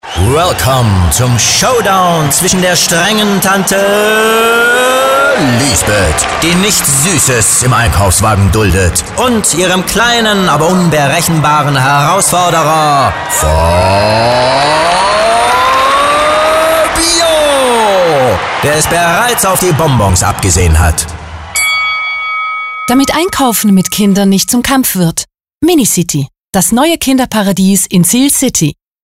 Weiter erzählen actionreiche Radiospots, warum sich auch Eltern über das neue Kinderparadies freuen können.